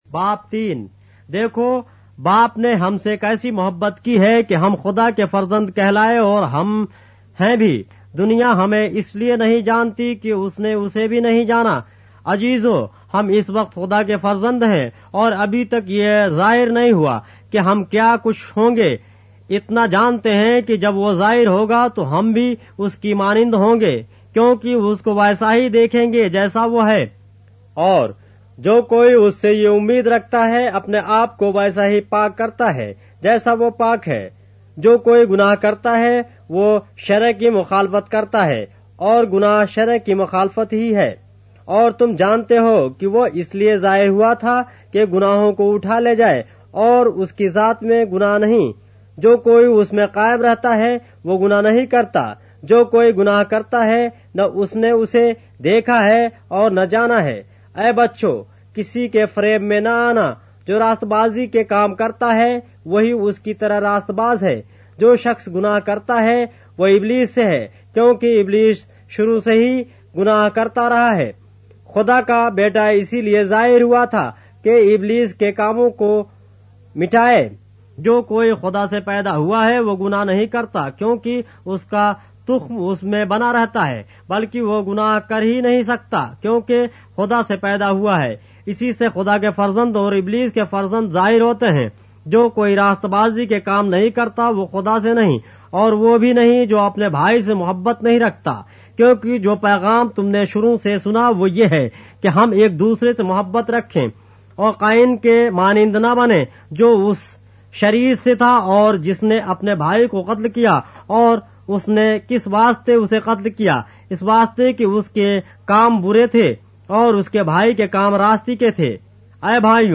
اردو بائبل کے باب - آڈیو روایت کے ساتھ - 1 John, chapter 3 of the Holy Bible in Urdu